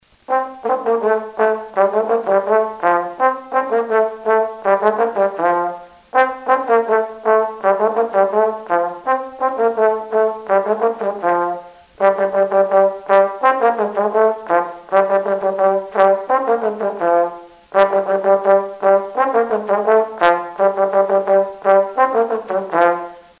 Aerófonos -> Vibración labios (trompeta) -> Cromáticos
Hiru pistoidun urre-koloreko tronboi arrunta da.